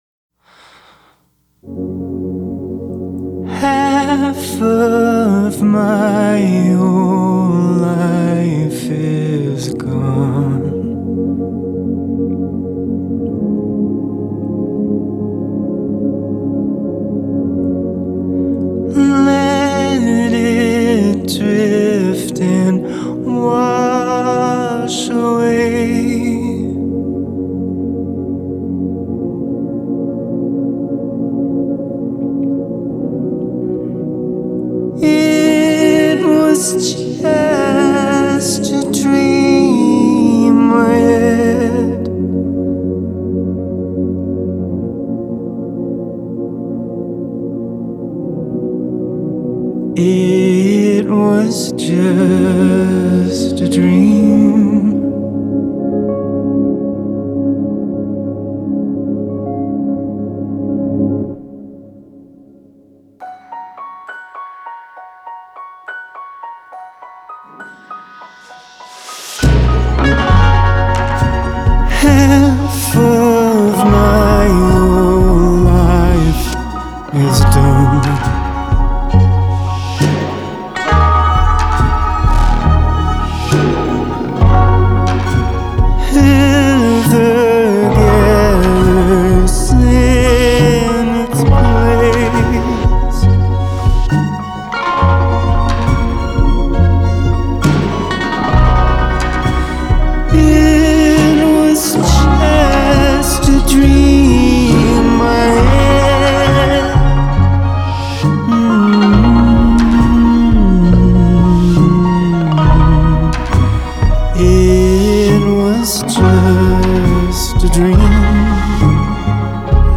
Genre : Alt. Rock